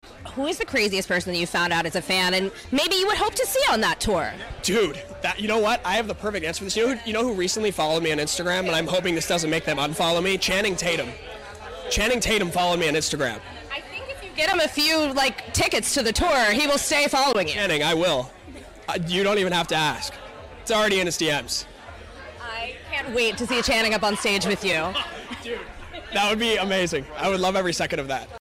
Variety Power Of Young Hollywood